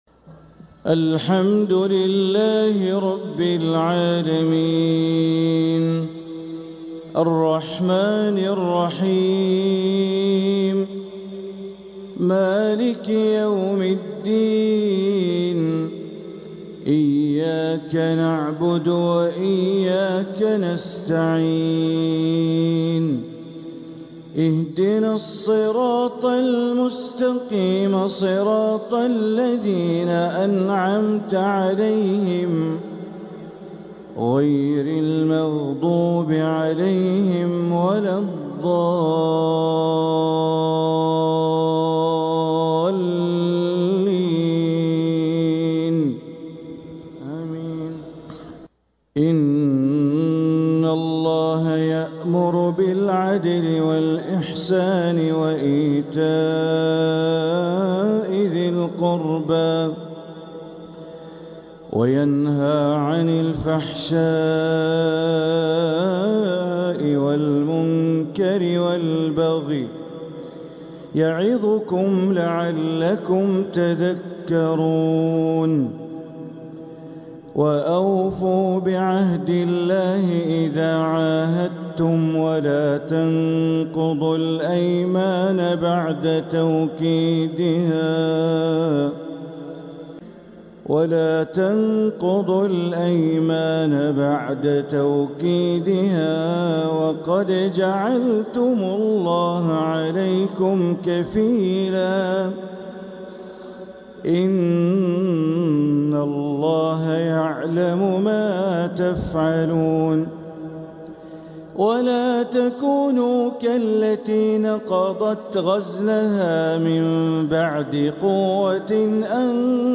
سلسلة اللآلئ الأصيلة لتلاوات الشيخ بندر بليلة لتلاوات شهر شوال 1446 الحلقة الخامسة والسبعون > سلسلة اللآلئ الأصيلة لتلاوات الشيخ بندر بليلة > المزيد - تلاوات بندر بليلة